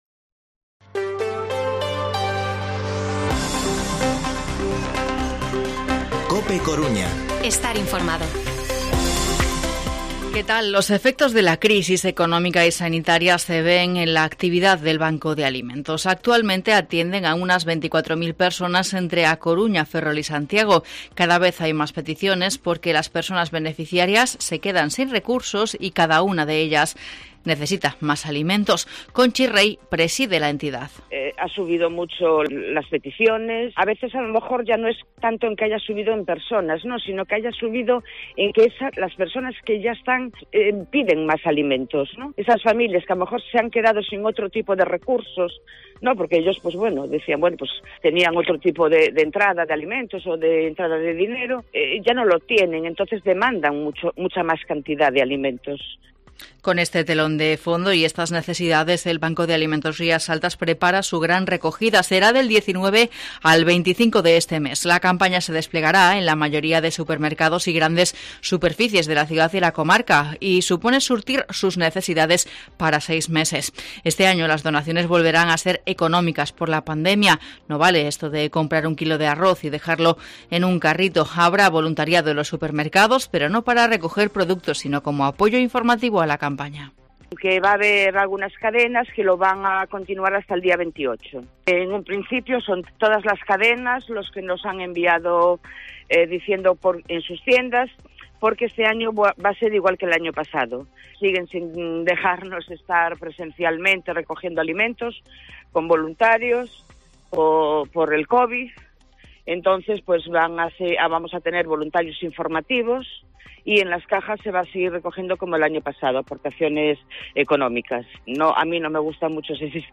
Informativo Mediodía COPE Coruña miércoles, 10 de noviembre de 2021 14:20-14:30